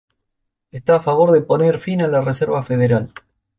po‧ner
/poˈneɾ/